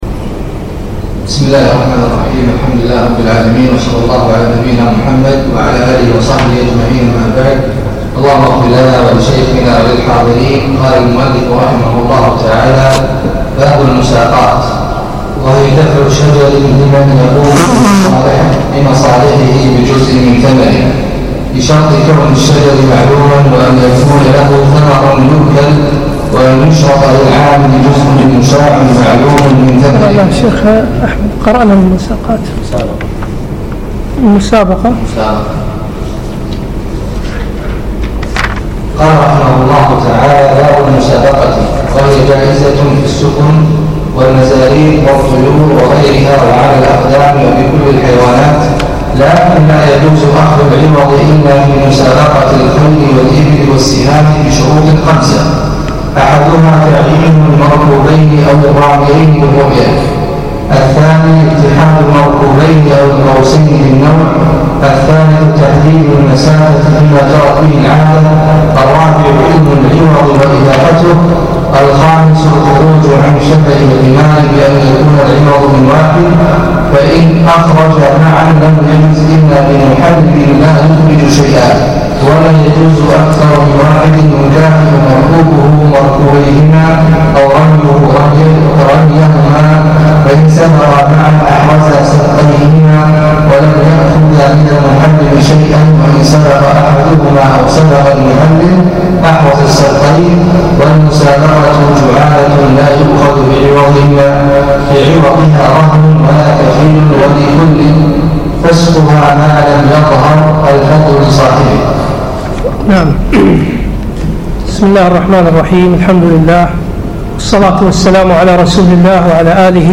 يوم السبت 9 4 2016 بمسجد سالم العلي الفحيحيل